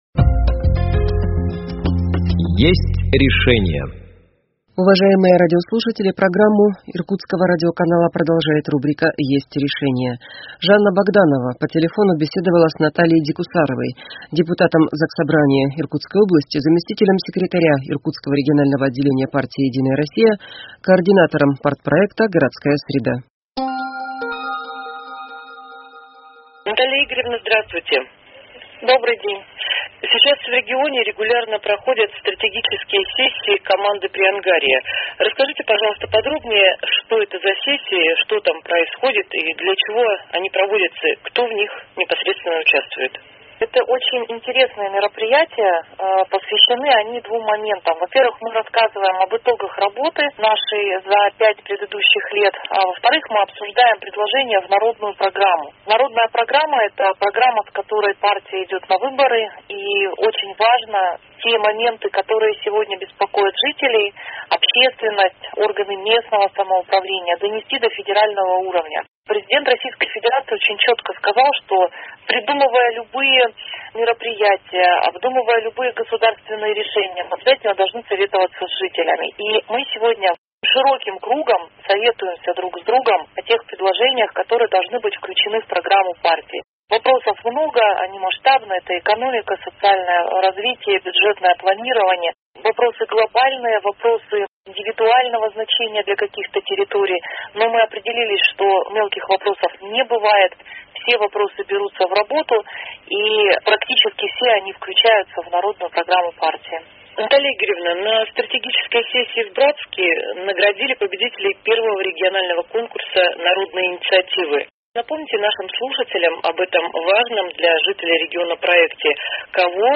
О стратегических сессиях «Команды Приангарья», о прямой линии губернатора Иркутской области и о проекте «Новая школа» рассказала депутат ЗС Иркутской области, заместитель секретара ИРО «Единая Россия», координатор партпроекта «Городская среда» Наталья Дикусарова.